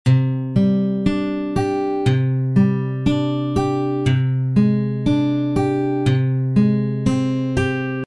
guitar_test.mp3